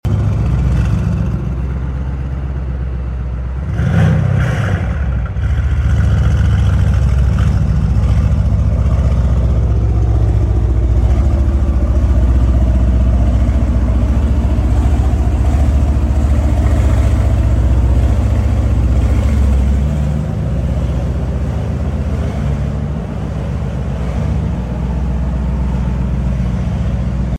🥶 COLD START C63 S sound effects free download